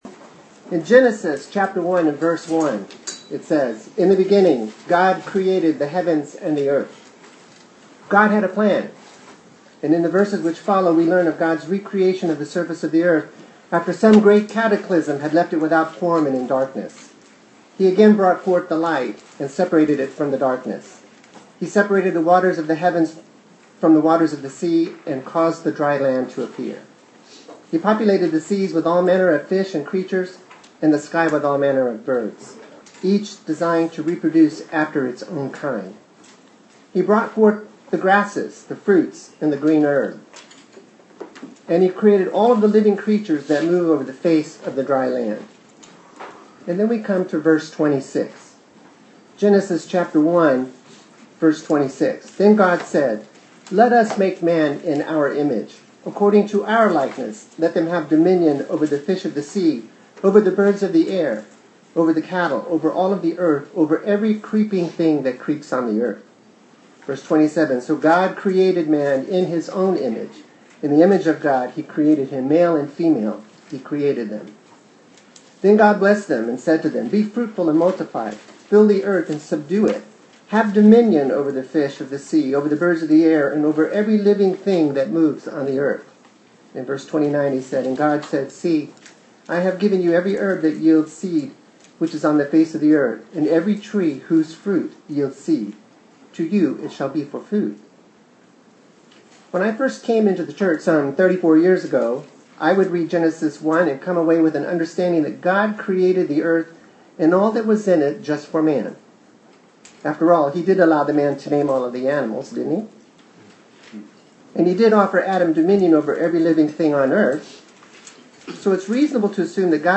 Given in Austin, TX
UCG Sermon Studying the bible?